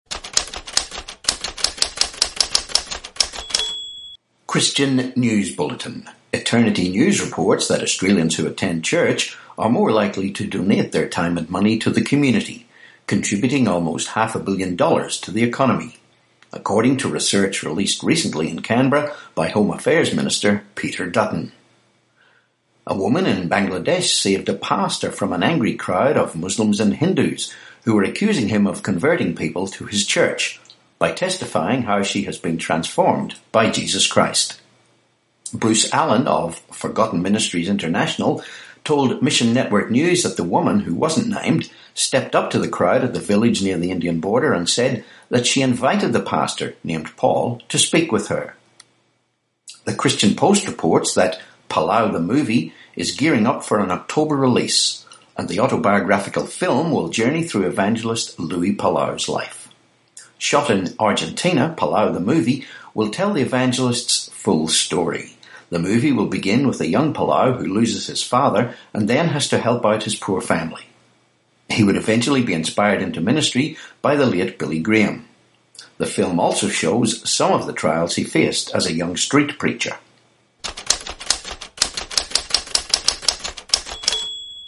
17Jun18 Christian News Bulletin